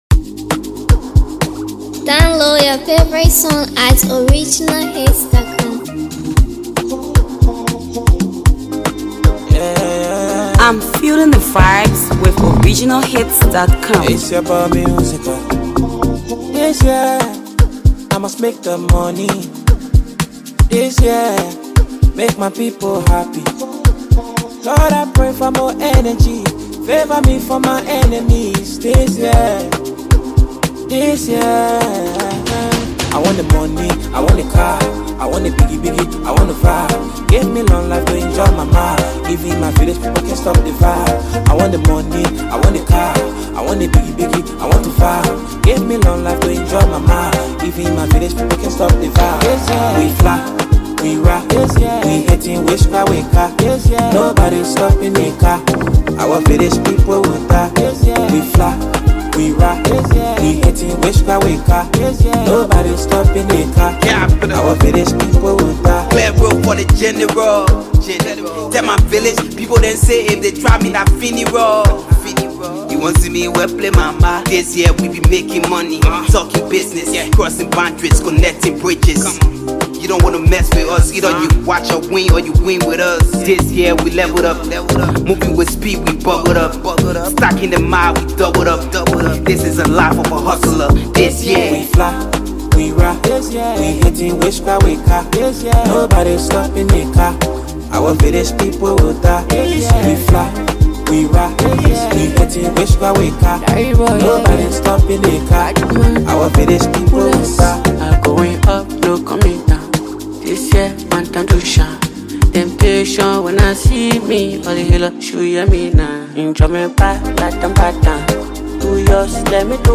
Hipco rapper